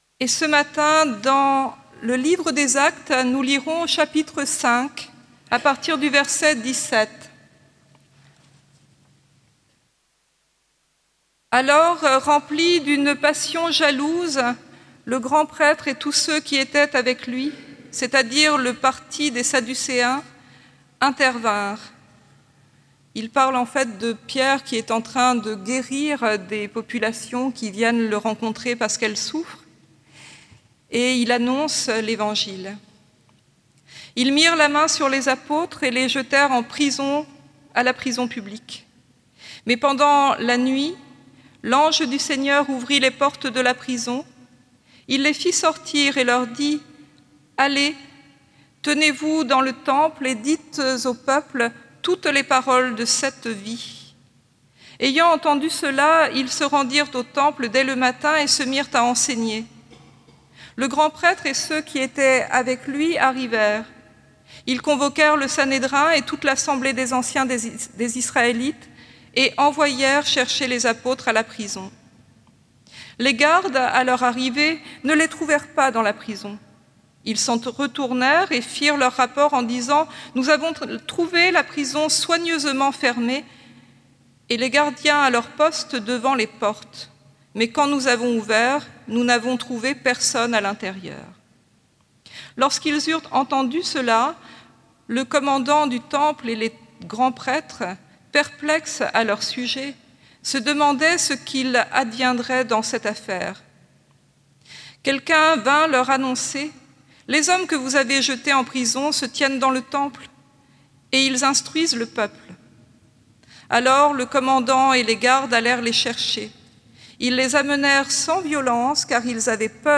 Culte du dimanche 26 août 2018